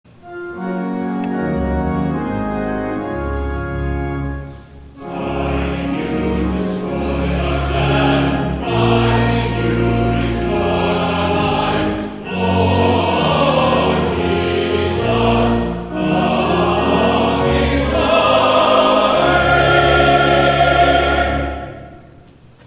11 A.M. WORSHIP
*THE CHORAL RESPONSE